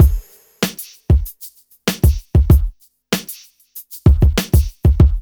4RB96BEAT2-L.wav